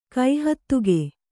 ♪ kai hattuge